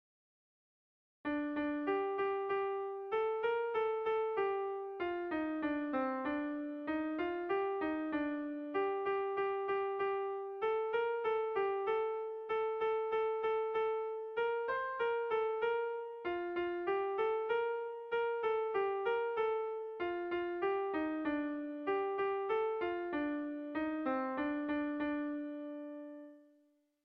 Bertso eta doinu polita.
Zazpi puntukoa, berdinaren moldekoa
ABDD